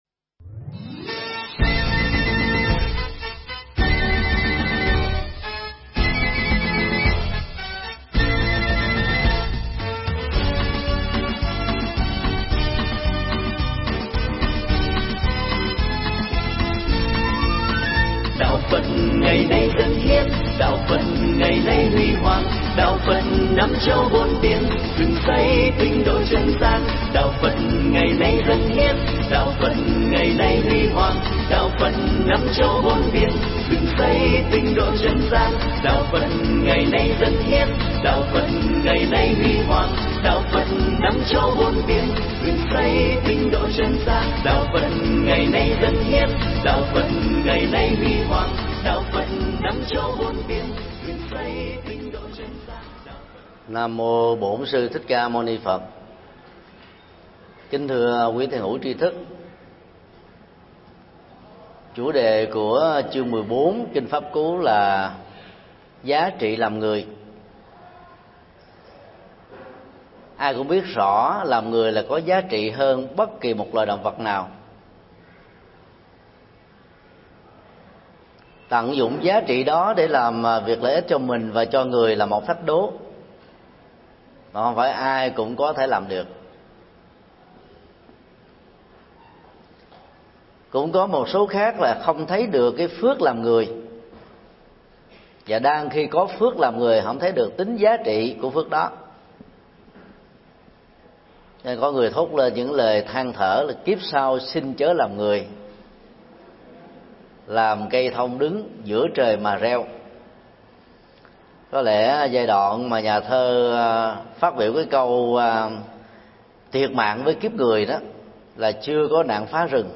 giảng tại chùa Bát Mẫu, Hà Nội, ngày 09 tháng 04 năm 2011